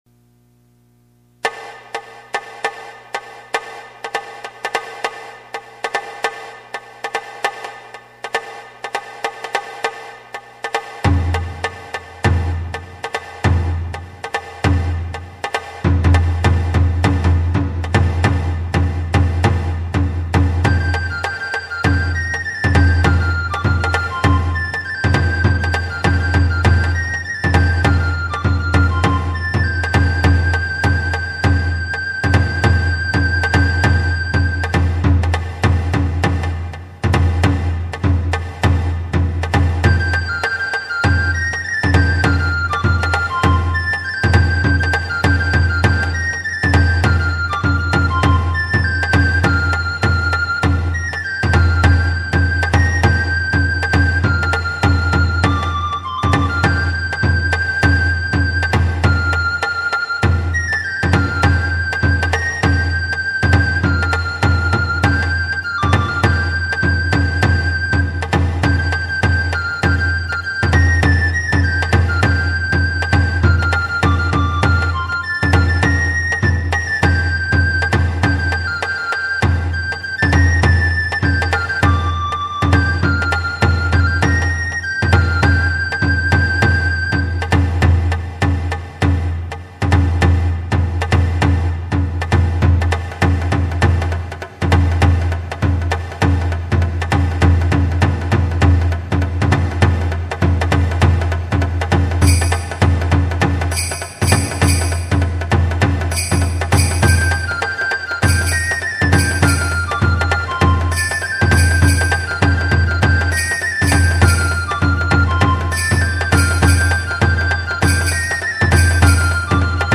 使用楽器　　中太鼓・締太鼓・しの笛